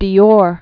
(dē-ôr), Christian 1905-1957.